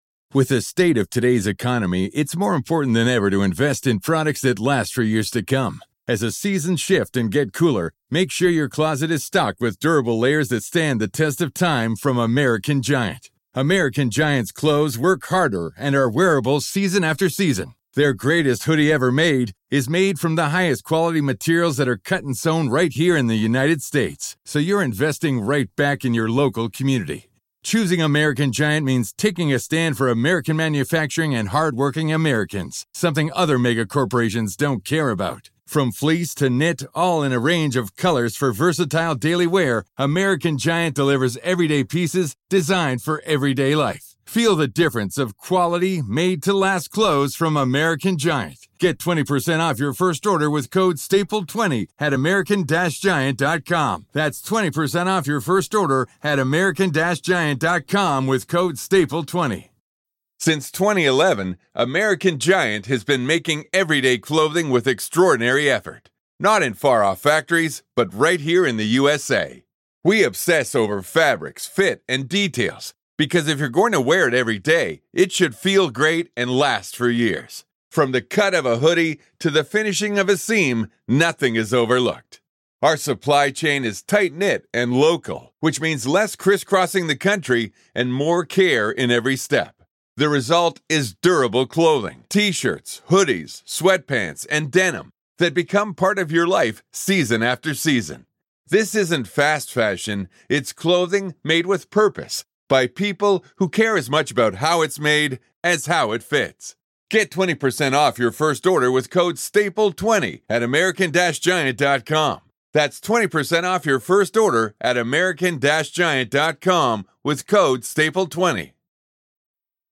You’ll hear unfiltered courtroom audio, direct from the trial